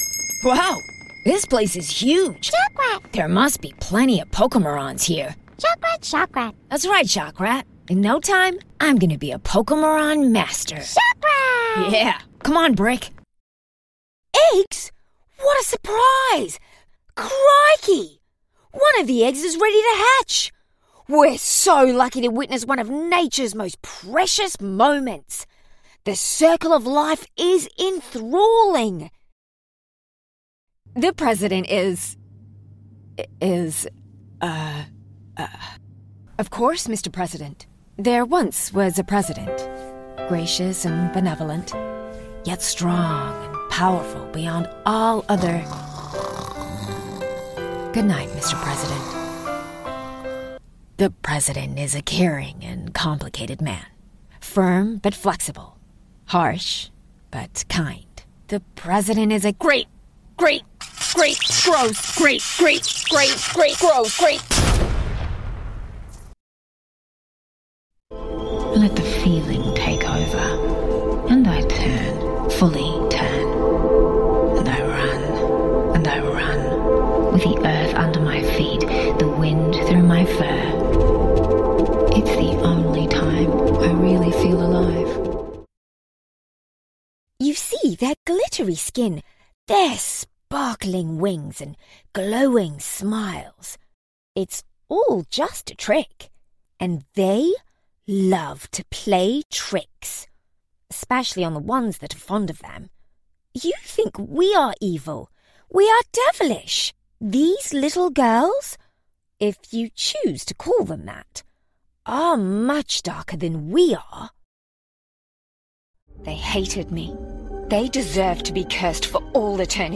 Character, Cartoon and Animation Voice Overs
Adult (30-50)
English (Australian)